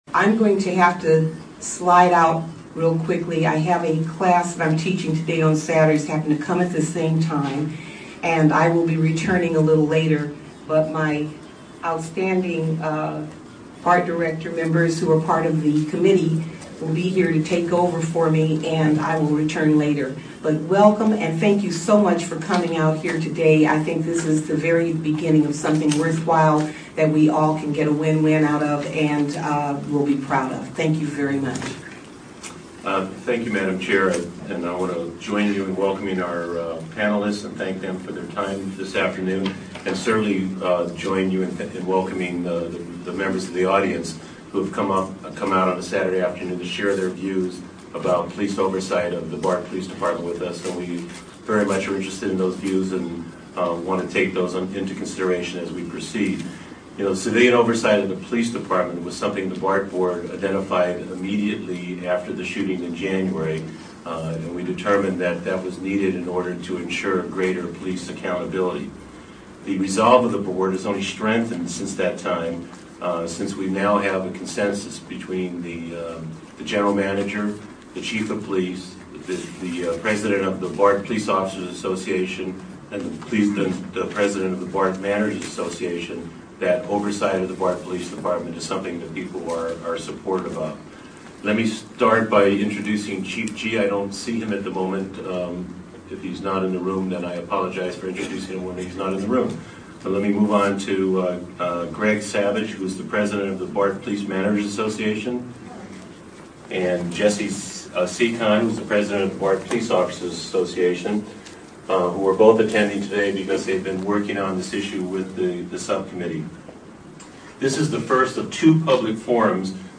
After months of pressure, BART held its first public meeting on police oversight on Saturday, May 2nd in Oakland. Several Bay Area models for police oversight were presented by representatives of the San Francisco Police Commission and Office of Citizens' Complaints, the Richmond Confidential Investigative and Appeals Office, the Sacramento Office of Public Safety Accountability, the Oakland Citizen's Review Board, the Berkeley Police Review Commission, and the ACLU of Northern California.